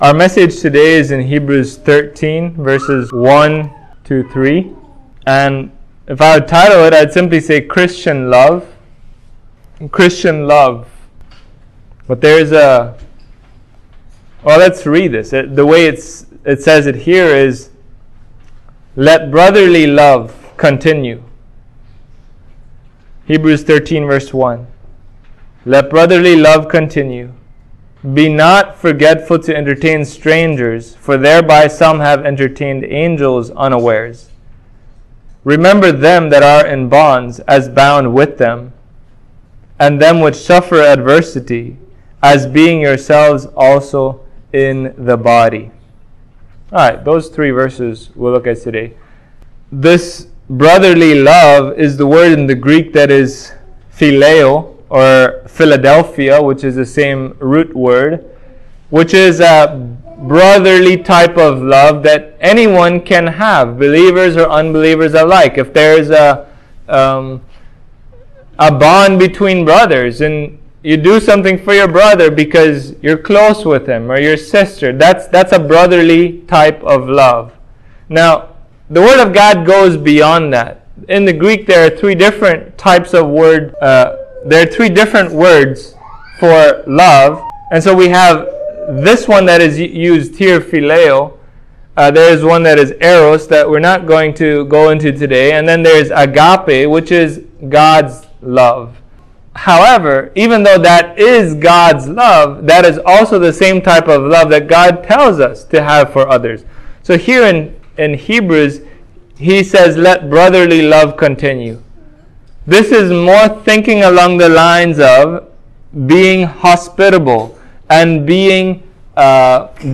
What, the, is Christian love, and how is it manifested outwardly? This sermon on Hebrews 13 addresses those questions.